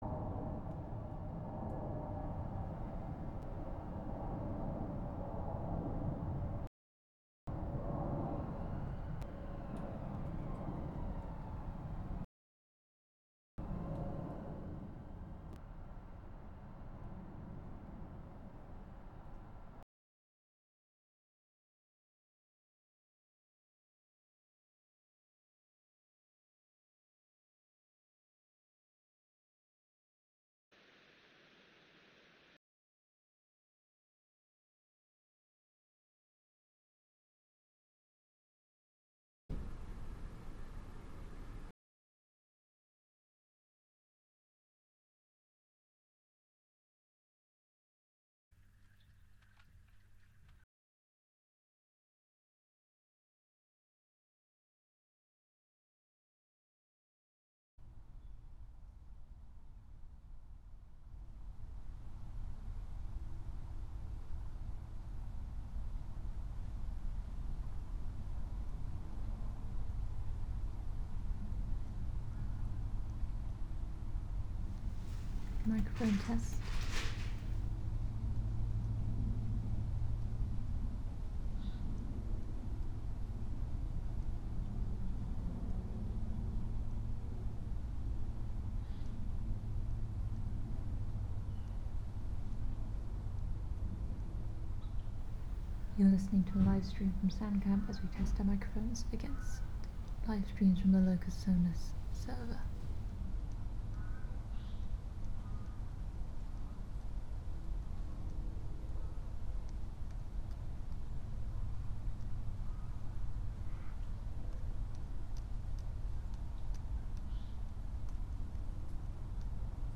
Live from Soundcamp: Radio With Palestine (Audio)